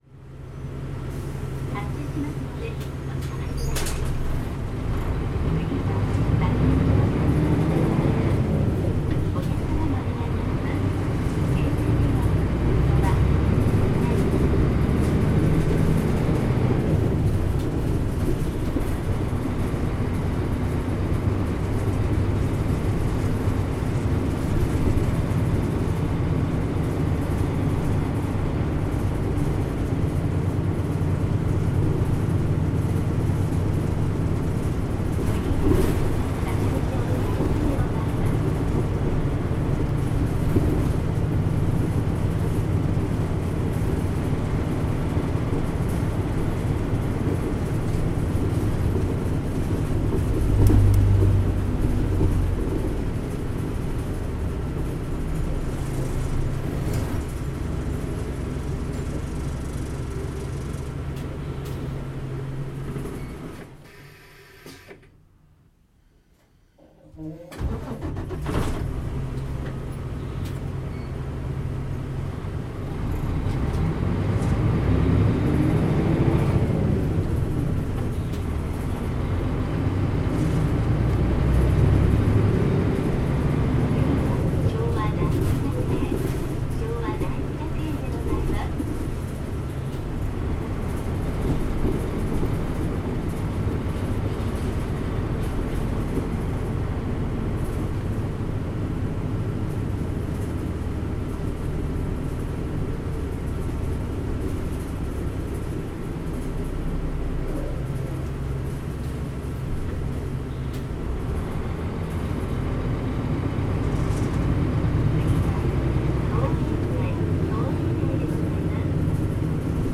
全国路線バス走行音立川バス
こちらはガラガラ音の強いターボ付きのPJ代ノンステップ車です。走行音はKL-MPの後期車と殆ど変りません。